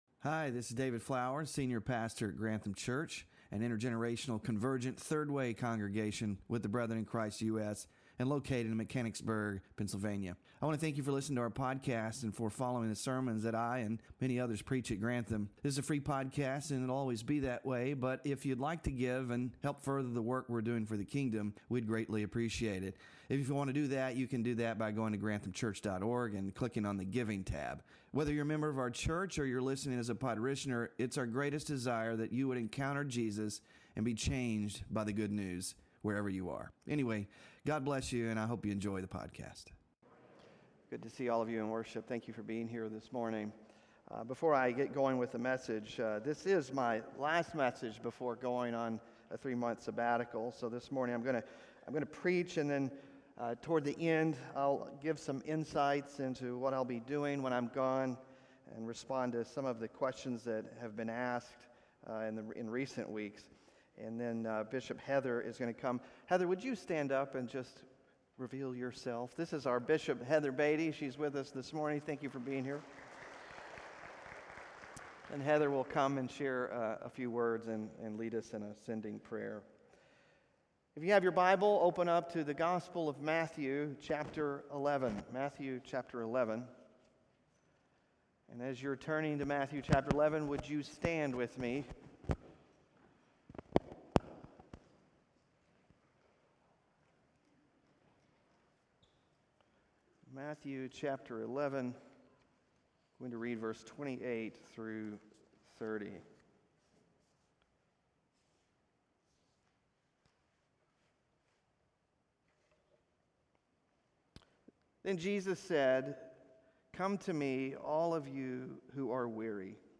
But what exactly does Jesus mean in Matthew 11:28-30? How do we enter the sabbath rest he offers, and experience his “yoke” of discipleship as life-giving? In his final message before going on a 3-month sabbatical